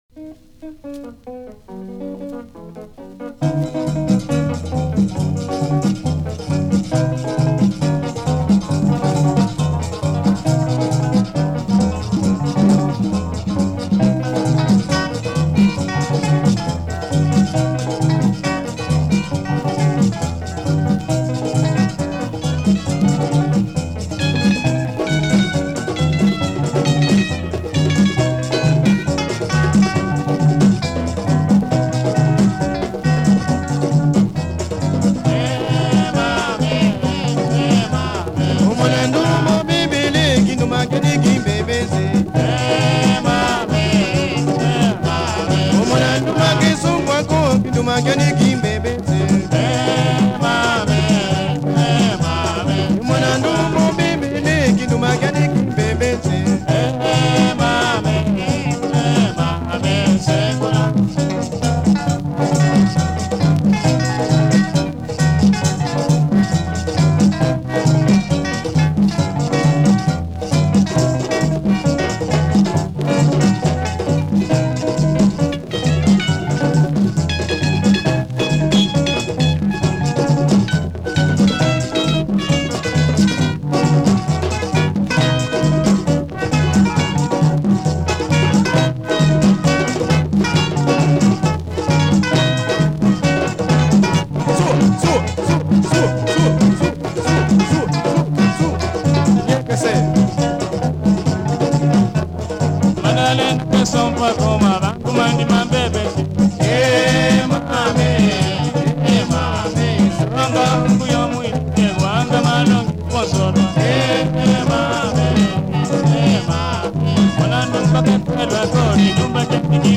lead vocalist
rhythm guitar
bass
percussion